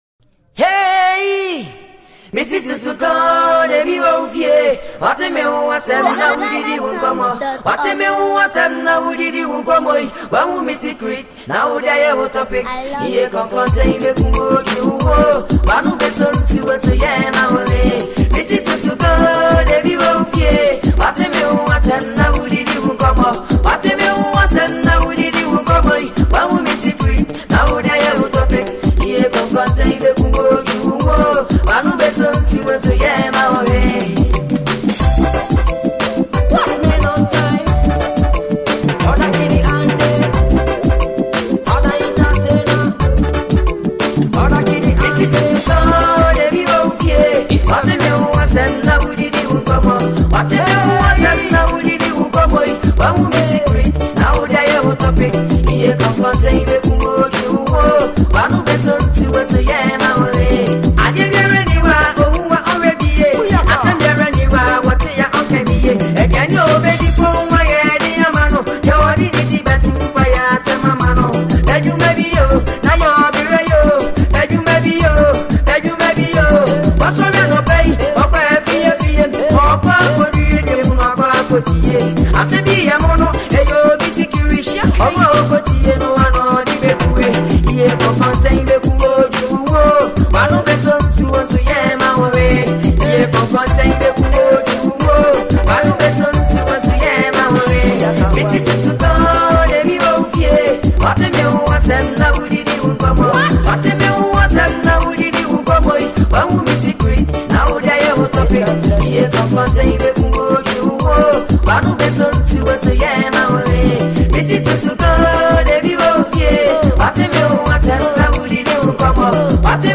Highlife & Afrobeat Vibes
With a perfect blend of highlife rhythm and Afrobeat groove